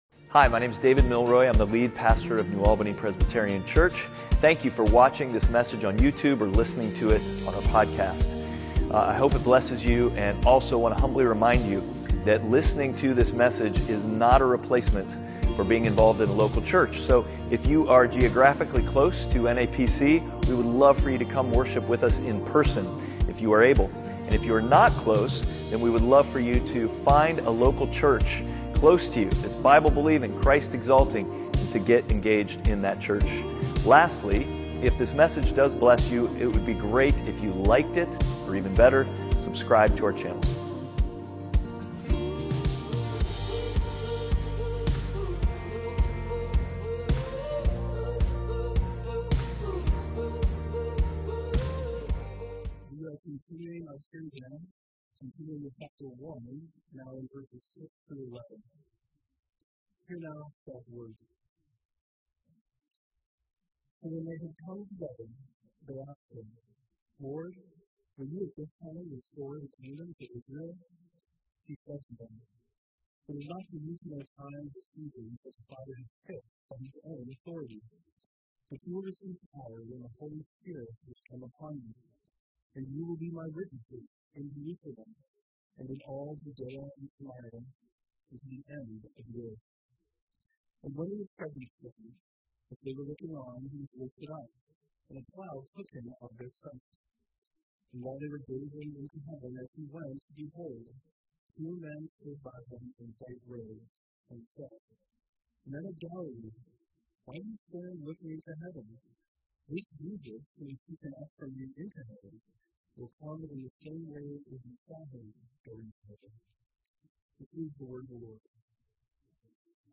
Outward Passage: Acts 1:6-11 Service Type: Sunday Worship « Outward